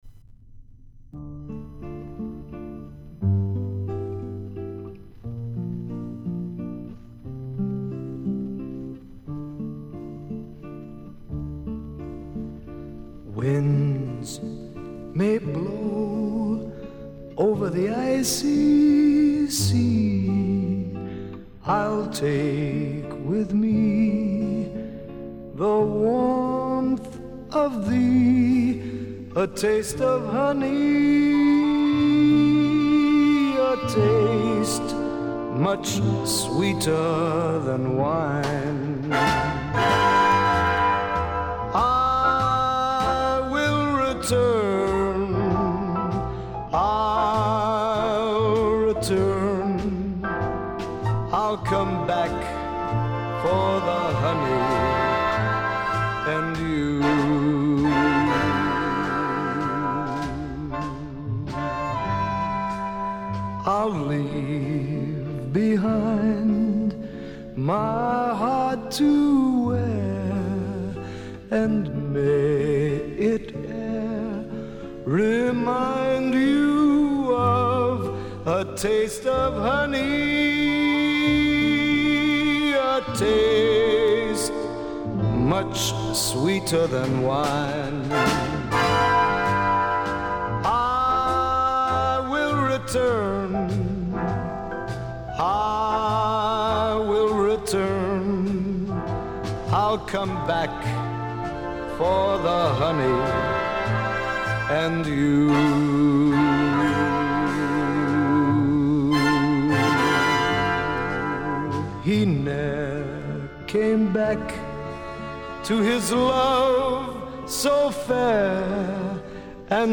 Жанры Свинг
Поп-музыка
Джаз